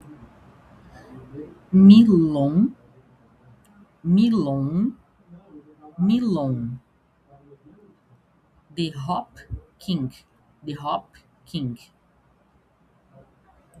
A pronúncia das duas marcas em negrita não ficaram legais.